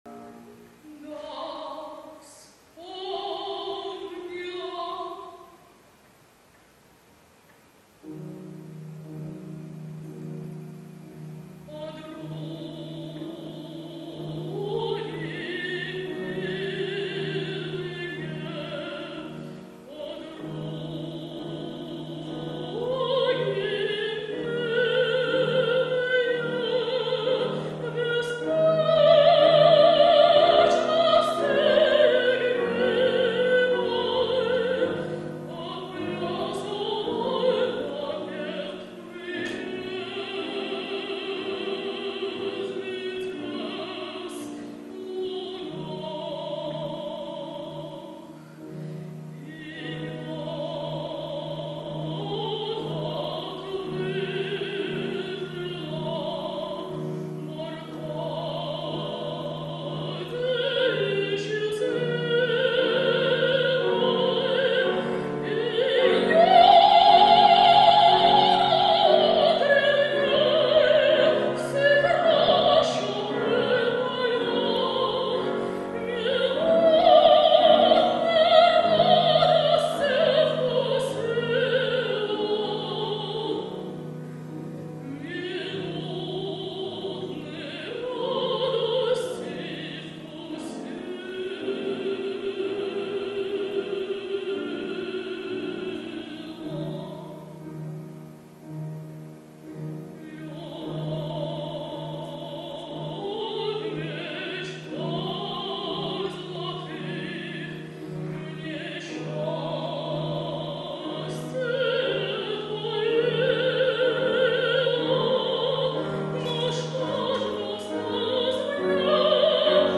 контральто